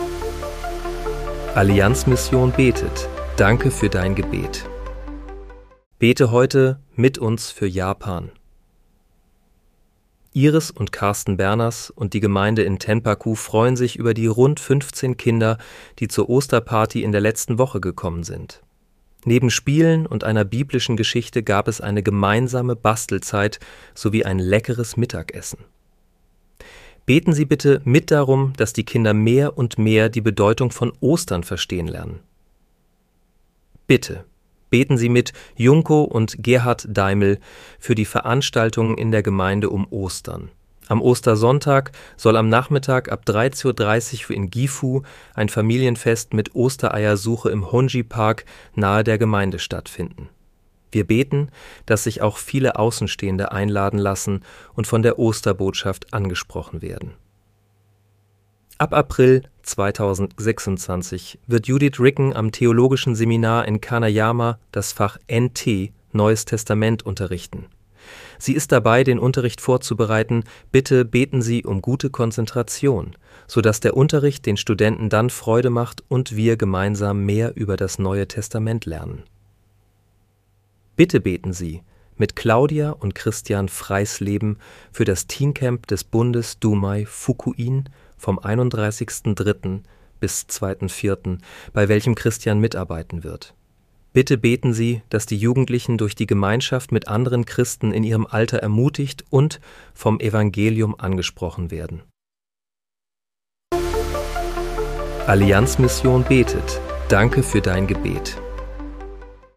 Bete am 31. März 2026 mit uns für Japan. (KI-generiert mit der